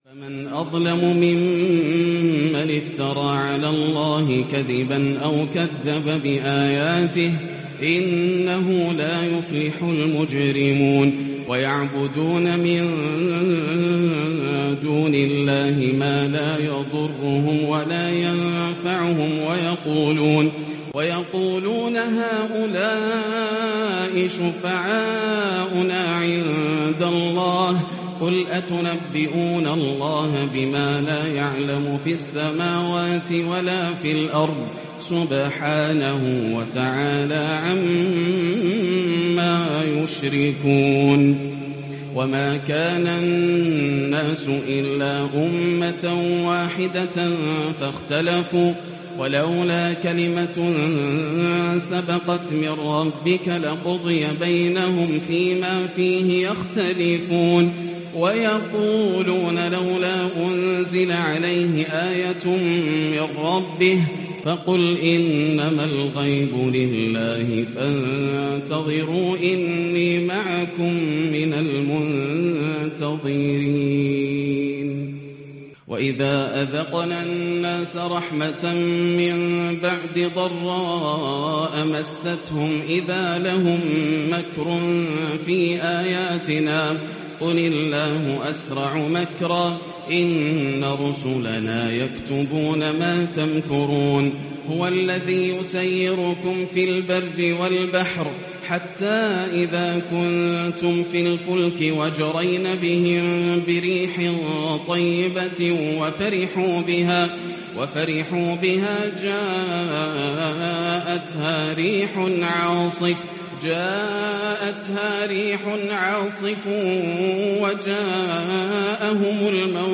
ليلة مميزة من سورتي التوبة و يونس روائع ليلة 14 رمضان1442هـ > الروائع > رمضان 1442هـ > التراويح - تلاوات ياسر الدوسري